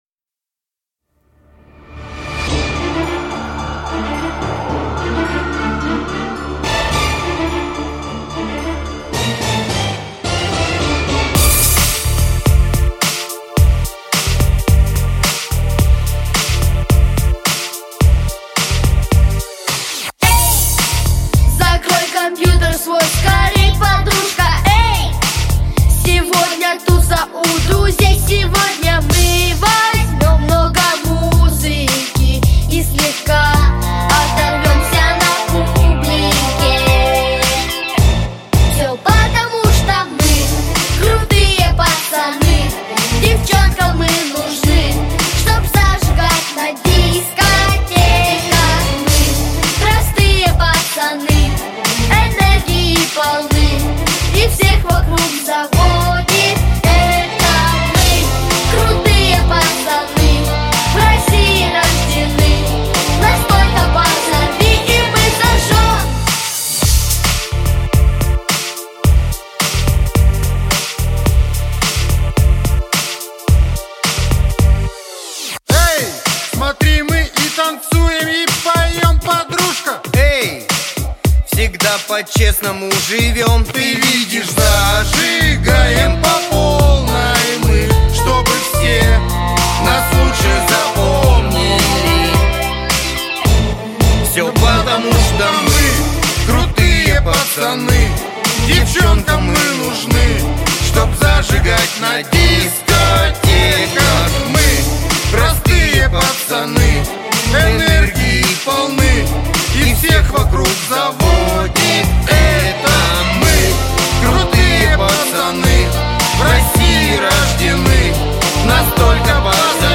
• Жанр: Детские песни
Песни в исполнении детской музыкальной студии